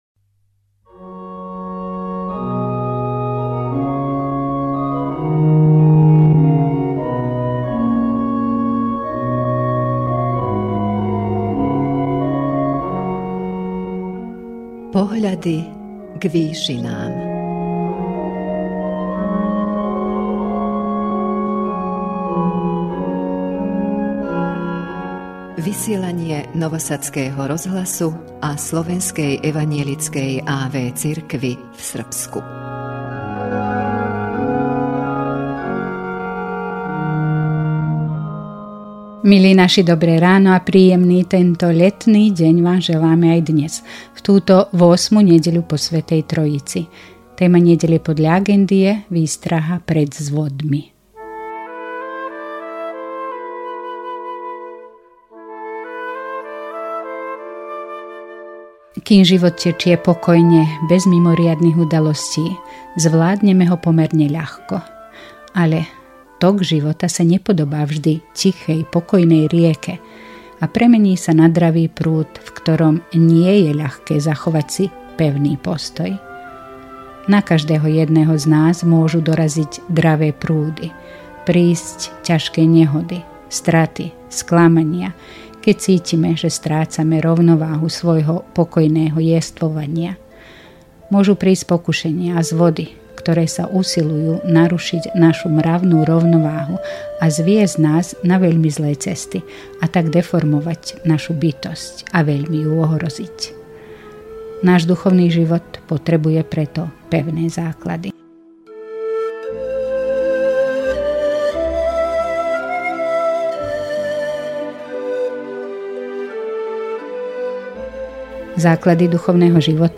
V duchovnej relácii Pohľady k výšinám Rádia Nový Sad a Slovenskej evanjelickej a.v. cirkvi v Srbsku v túto 8. nedeľu po Svätej Trojici duchovnú úvahou